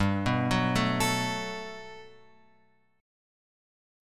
G Major 9th